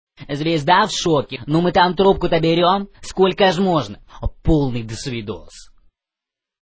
Категория: Рингтоны пародии